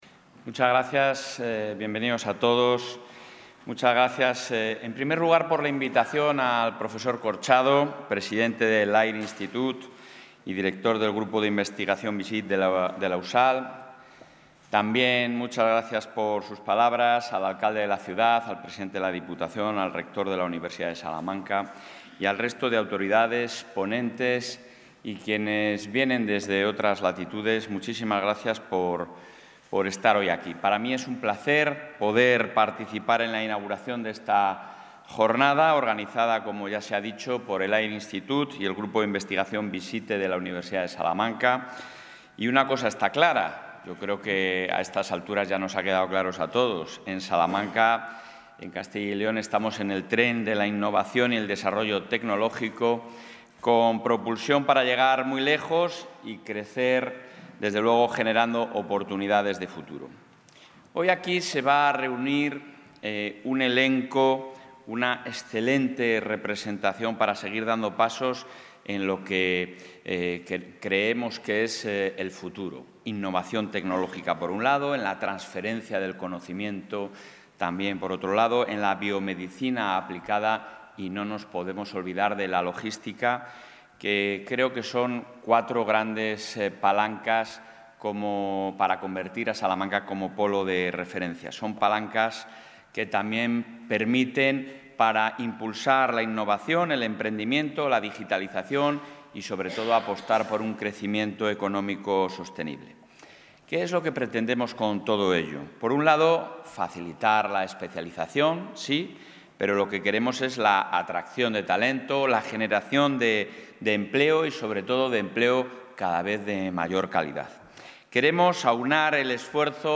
Intervención del presidente de la Junta.
El presidente de la Junta ha participado hoy, en el Palacio de Congresos de Salamanca, en la apertura del foro de innovación ‘Salamanca TECH DAY’, una jornada organizada por el AIR Institute y el Grupo de Investigación BISITE de la Universidad de Salamanca.